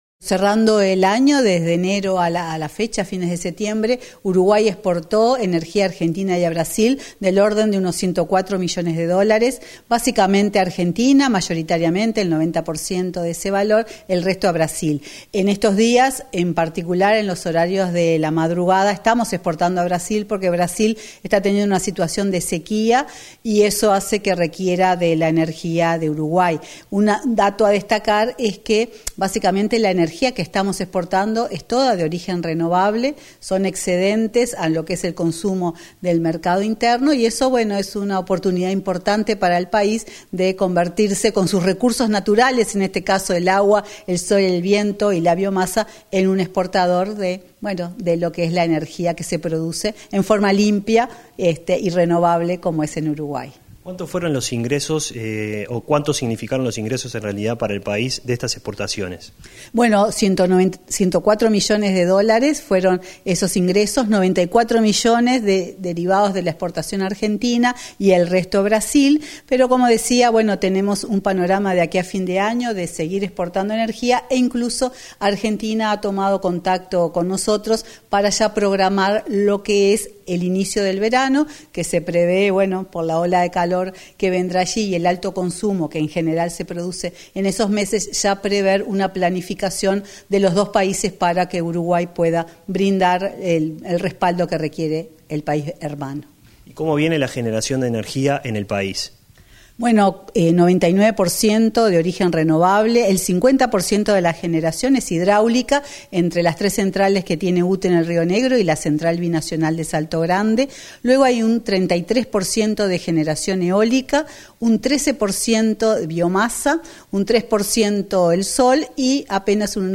Entrevista a la presidenta de UTE, Silvia Emaldi